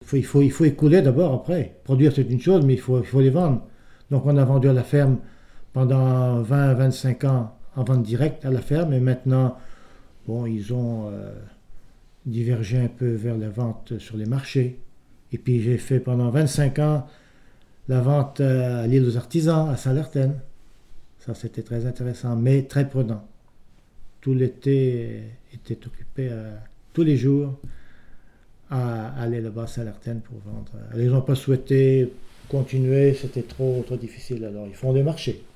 RADdO - La vente de la production de foies gras et de confits - Document n°232518 - Témoignage
Il provient de Saint-Gervais.